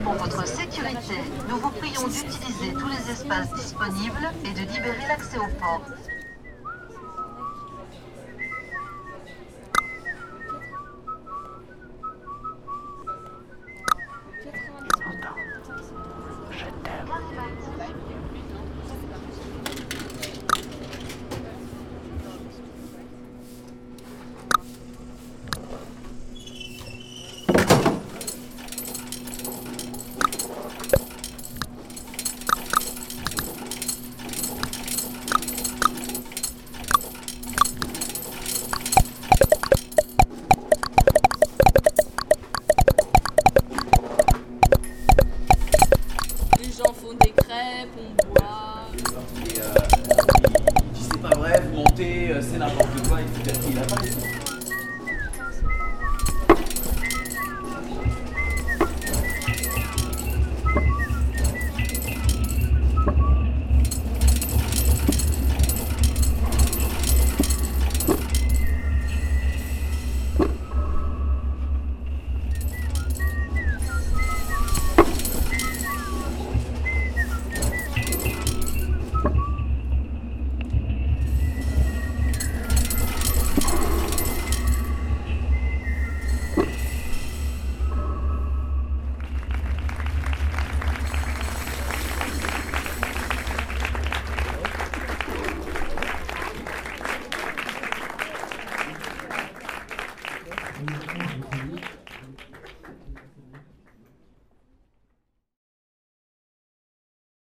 Petites fictions, ambiances propres au campus, paroles relatives à la vie universitaire, le tout dans des ambiances un rien décalées... les vignettes sonores vous proposent une "audio-vision" ludique du campus.
12 - Mixage
13 - Sifflements.mp3